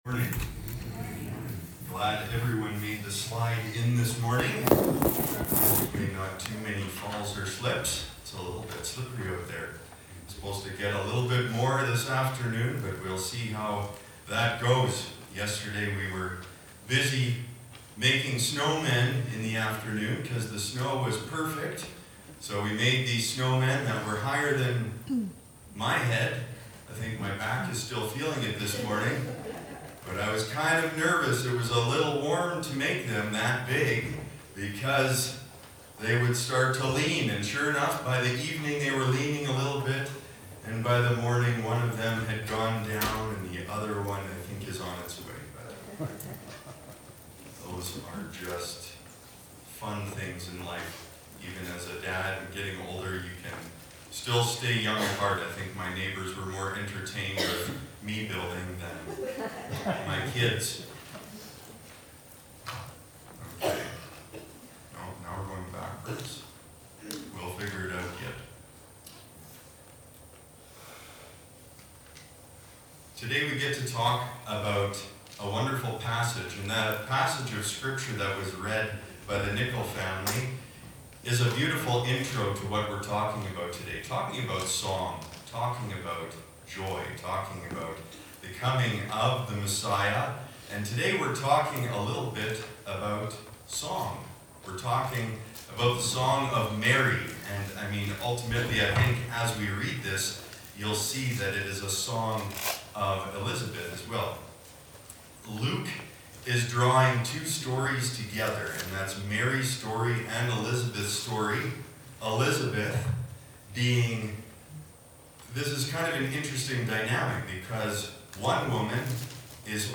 Sermons - Whiteshell Baptist Church